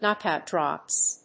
アクセントknóckout dròps